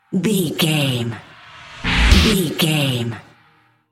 Aeolian/Minor
D
drums
bass guitar
hard rock
lead guitar
aggressive
energetic
intense
nu metal
alternative metal